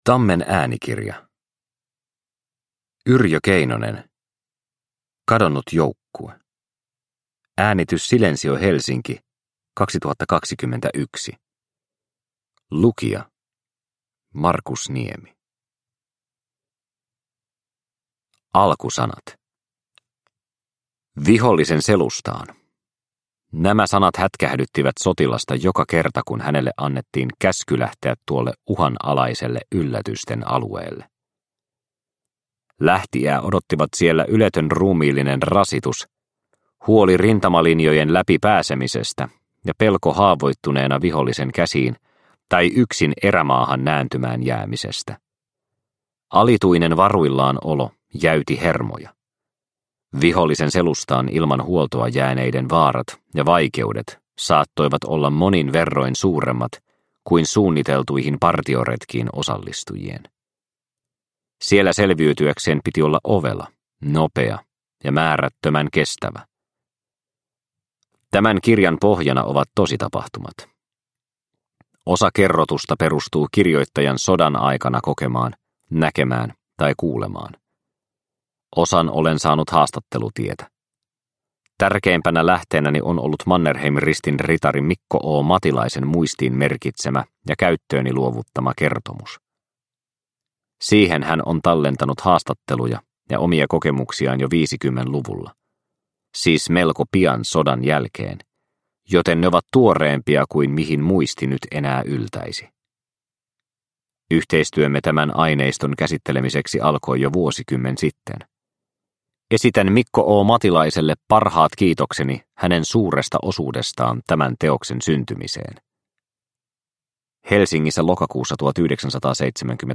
Kadonnut joukkue – Ljudbok – Laddas ner